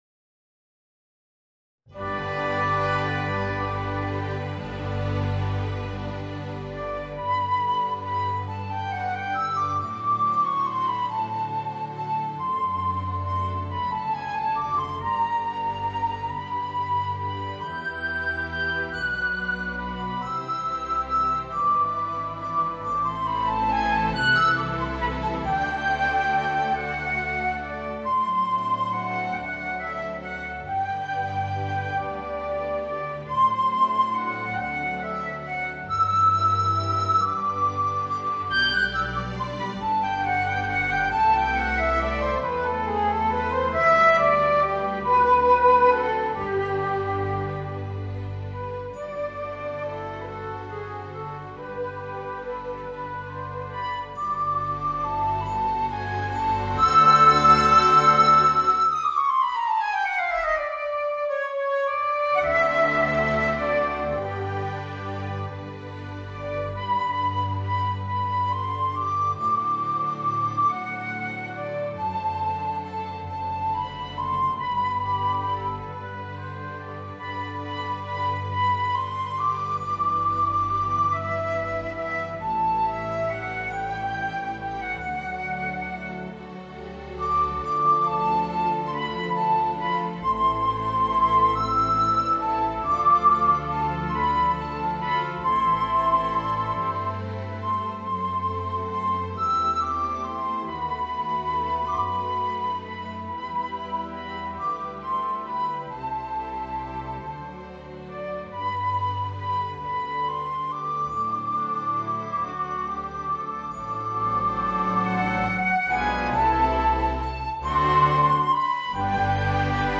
Voicing: Piccolo and Orchestra